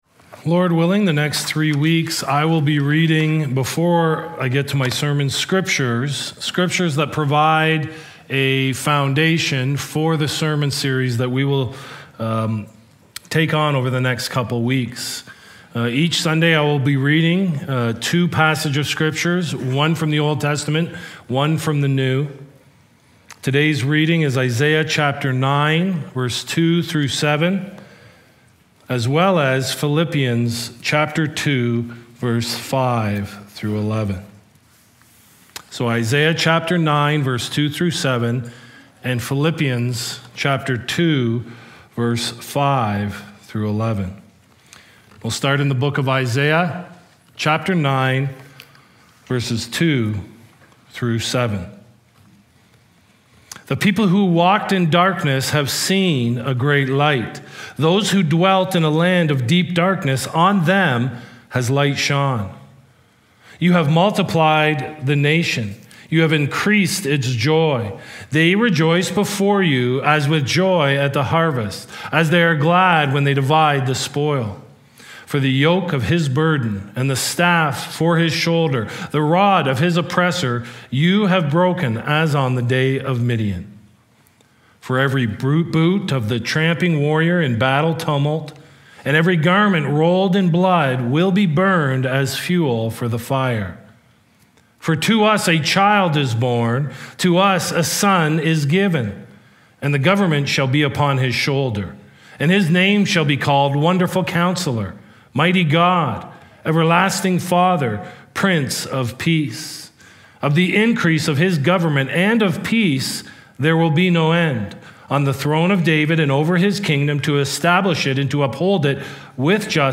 Sermon Archives Christ Came to Conquer the World